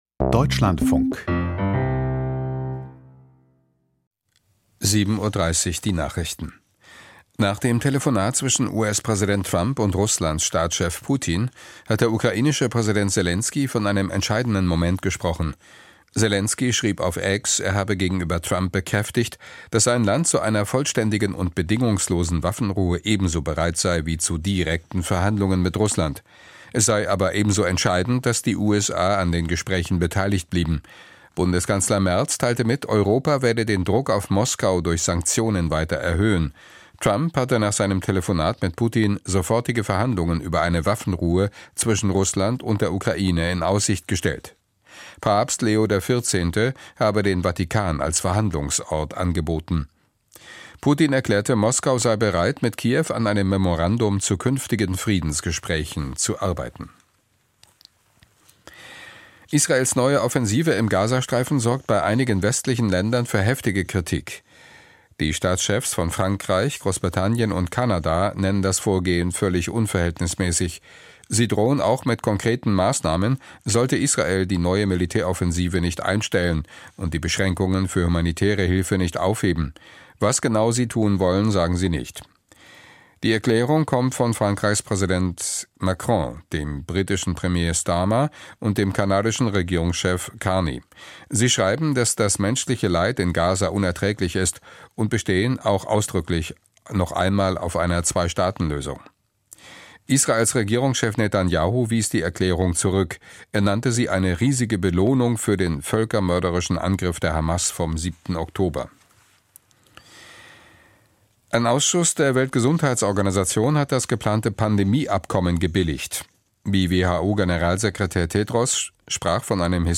Die Nachrichten vom 20.05.2025, 07:30 Uhr
Aus der Deutschlandfunk-Nachrichtenredaktion.